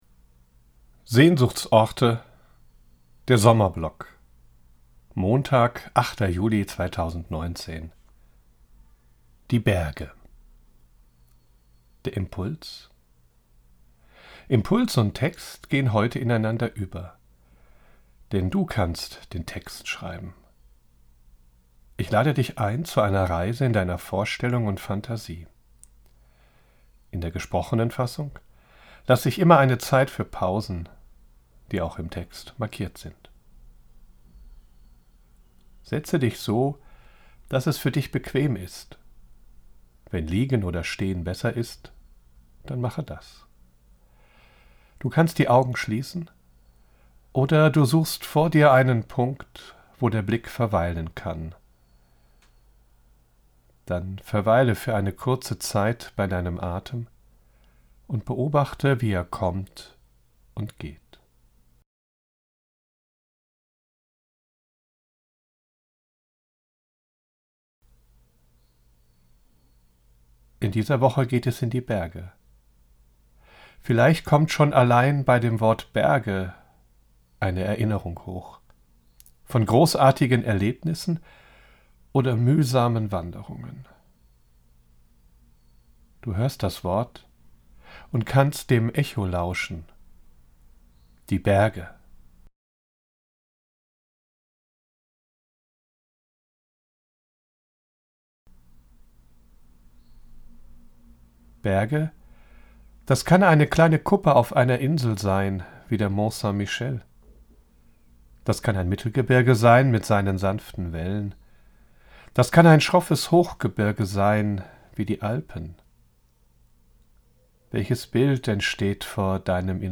Ich lade dich ein zu einer Reise in deiner Vorstellung und Fantasie. In der gesprochenen Fassung lasse ich immer eine Zeit für Pausen, die auch im Text markiert sind.